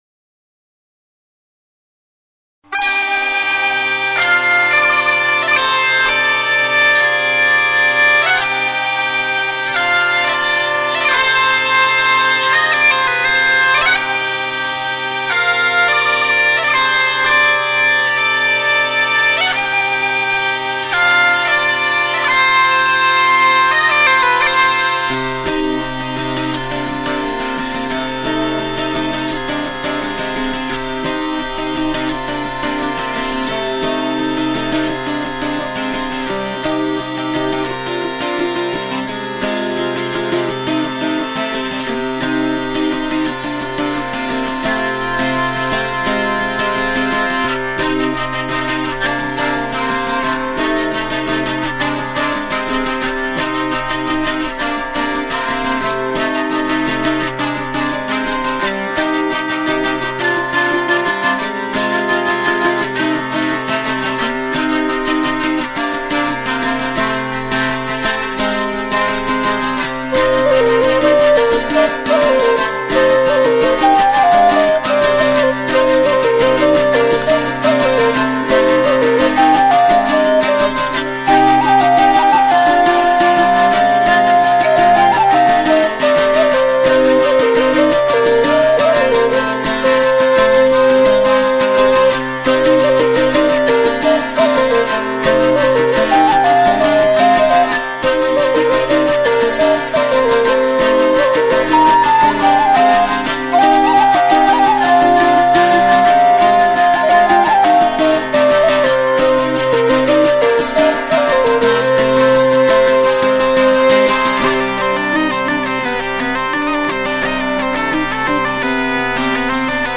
Scottishe